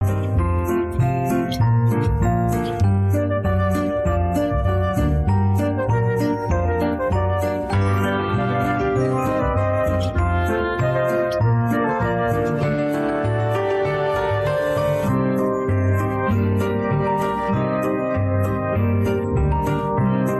هوش مصنوعی حذف صدای خواننده با lalal